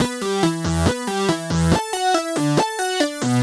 Index of /musicradar/french-house-chillout-samples/140bpm/Instruments
FHC_Arp C_140-E.wav